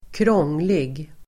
Uttal: [²kr'ång:lig]